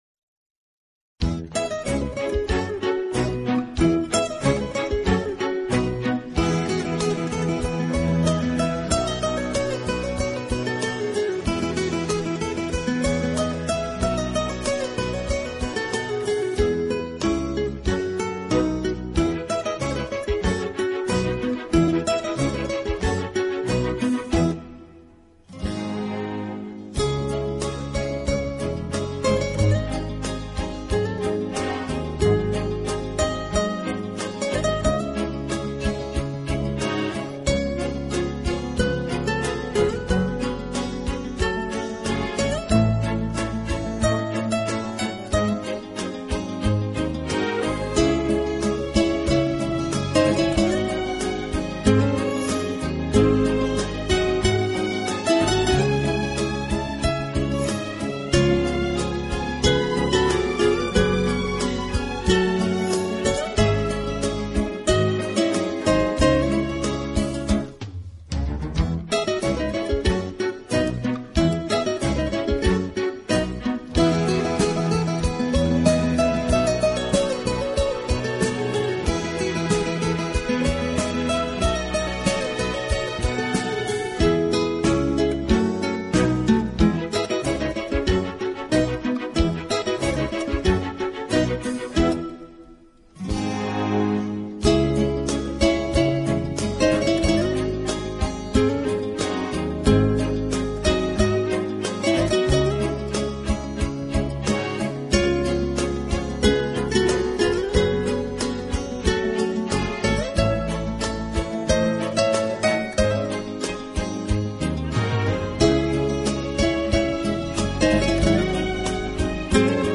十二集超过二百首流行音乐元素与世界各地风情韵味完美结合的音乐，